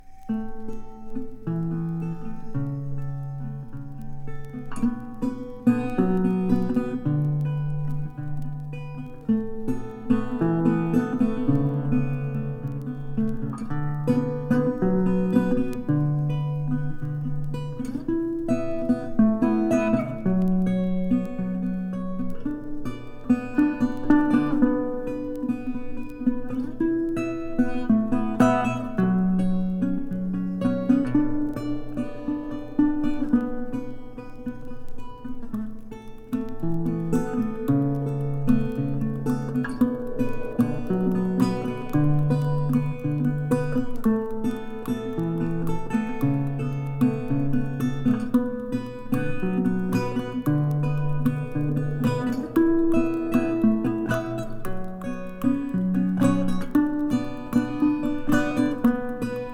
アンビエント質感もアリで、聴いていて心が洗われるような気持ちに、、、。